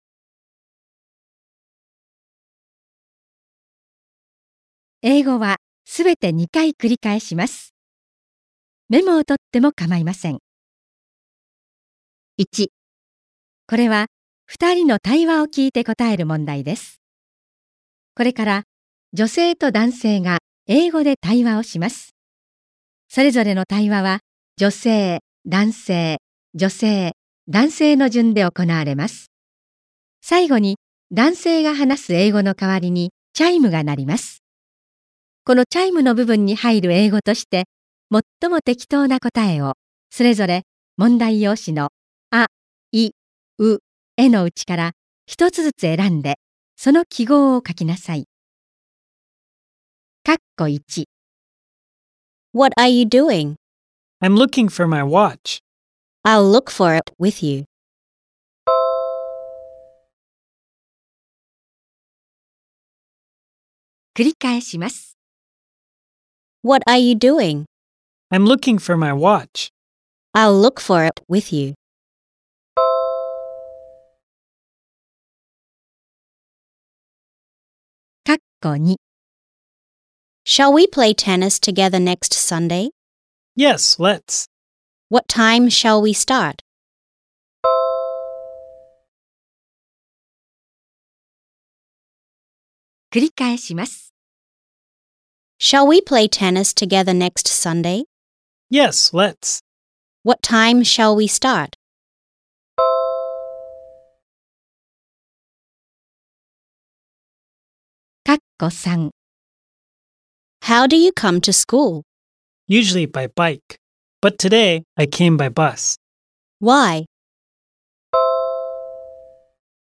2022年度受験用 岩手県公立高校入試予想問題集 第1回 英語リスニング問題 音声ダウンロード